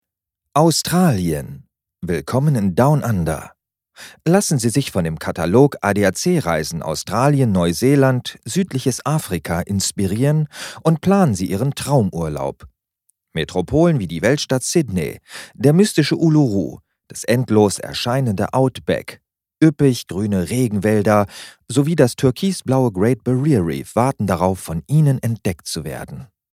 Da ich auch ein SM58 seit Anno Knax in der Scublade herumliegen habe, bin ich neugierig geworden und habe mal flugs was eingesprochen. Wenn man ihm etwas Dampf gibt (Preamp Gain 45 dB) dann sind die Ergebnisse garnicht so schlecht. PS: Ich habe das Mikro in der Hand gehalten und keinen Plopschutz verwendet.